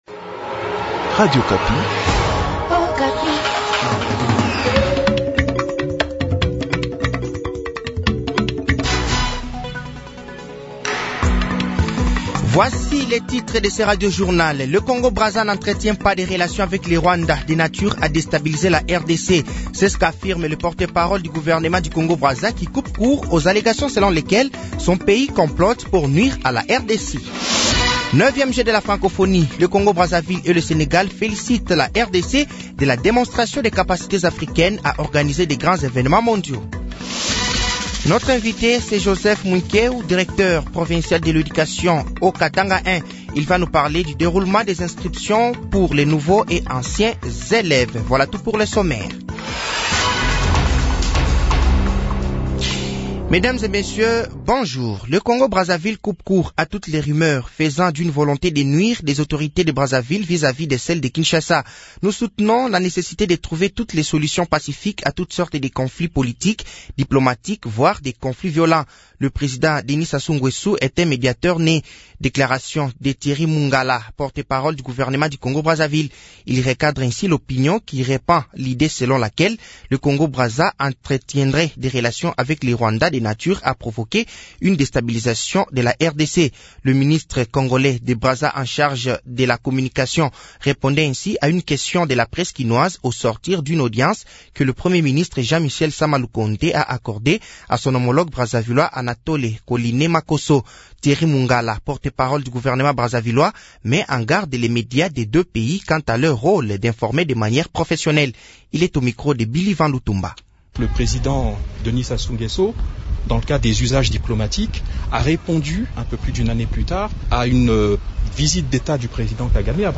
Journal français de 7h de ce dimanche 30 juillet 2023